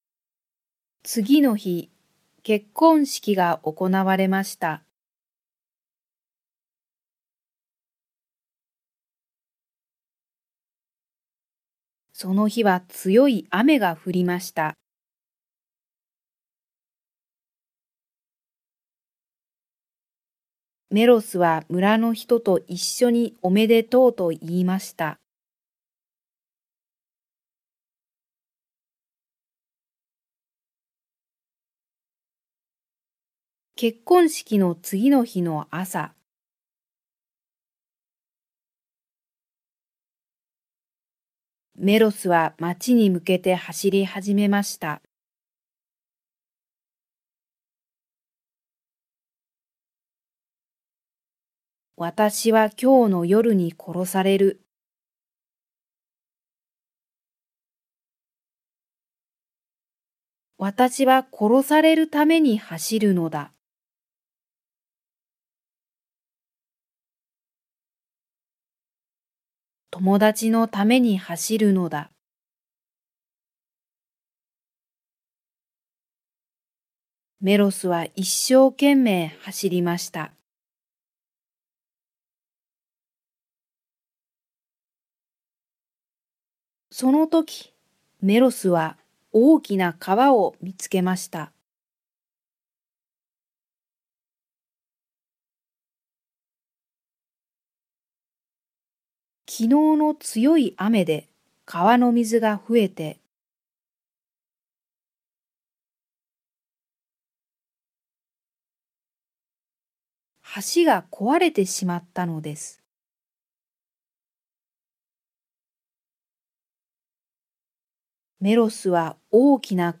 Japanese Graded Readers: Fairy Tales and Short Stories with Read-aloud Method
Natural Speed
Natural Speed with Pauses